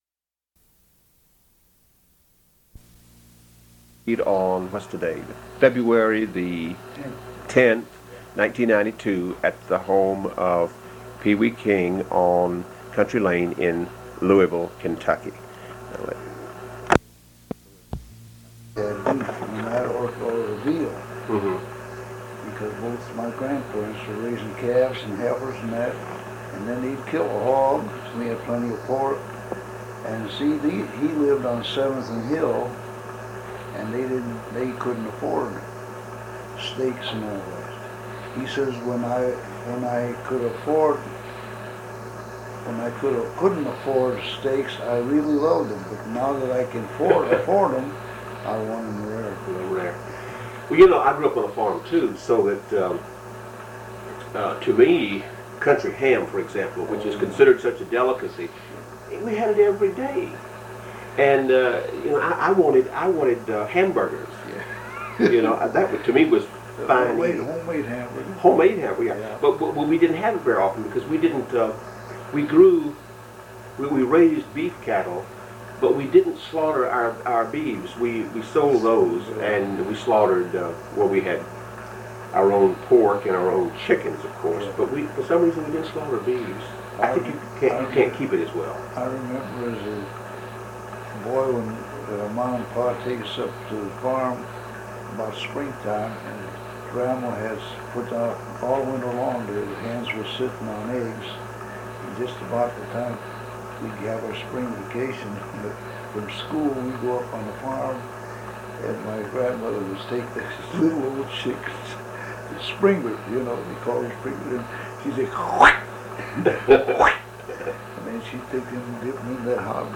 Oral History Interview with Pee Wee King